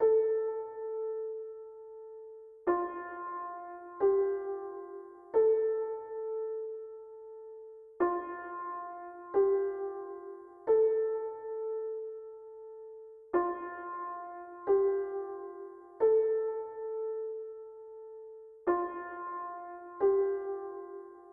海浪漱口水沟090714
描述：在山沟里录制海浪。Tascam DR100
标签： 海岸 现场录音 漱口 沟壑 怪物 波浪
声道立体声